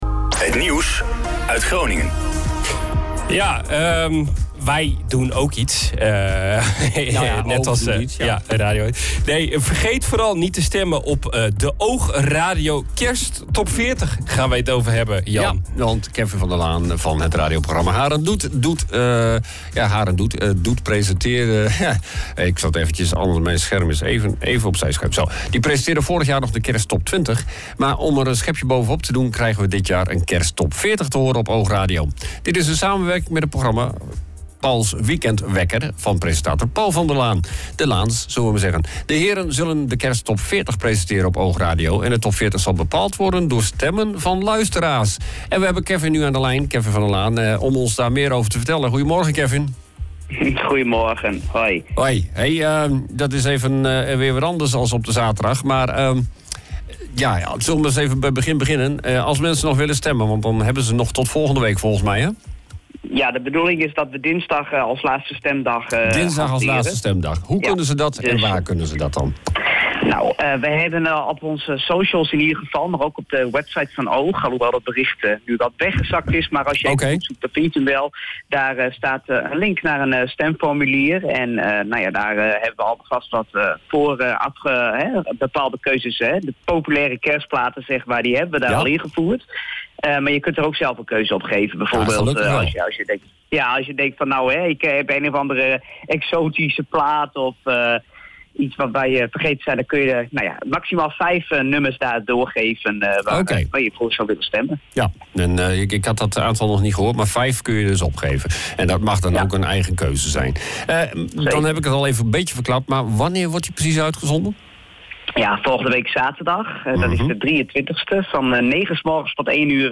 was te gast tijdens de OOG Ochtendshow